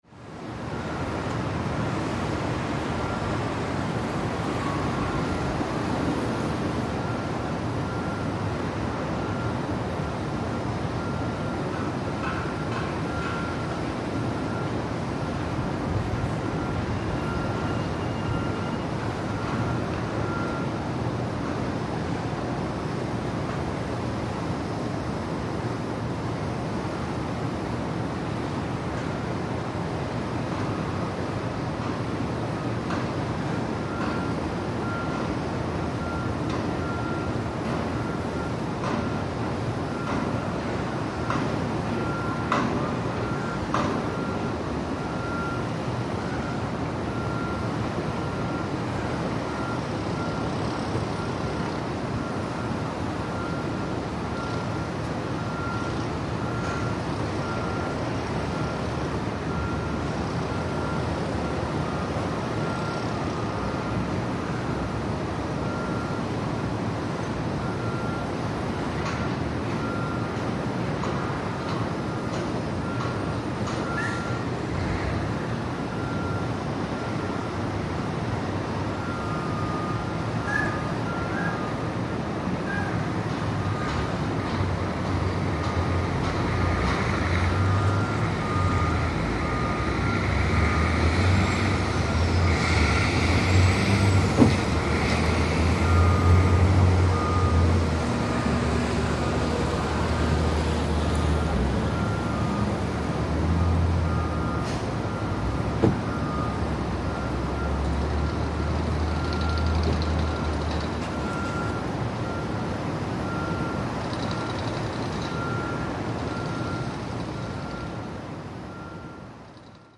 Звуки городской улицы
На этой странице собраны разнообразные звуки городской улицы: гул машин, голоса прохожих, сигналы светофоров и другие фоновые шумы мегаполиса.
Звуки улицы с высоты крыши или балкона в птичьем полете